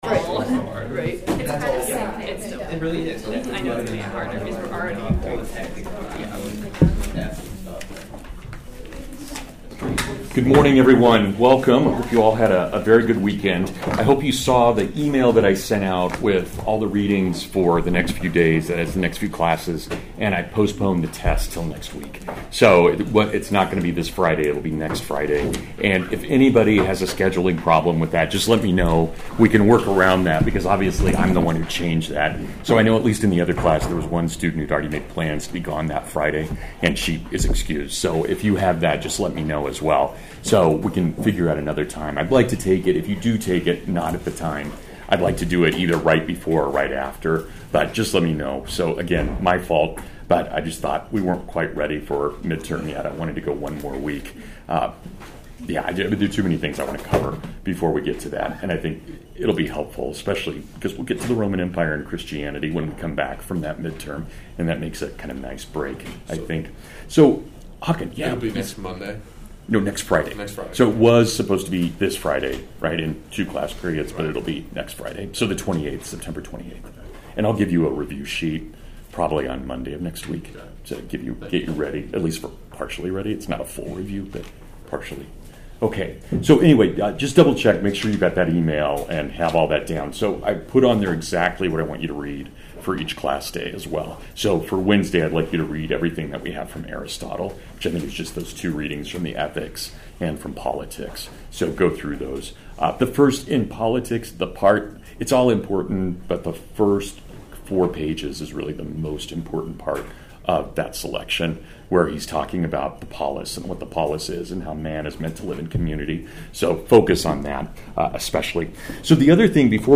The Crito (Full Lecture)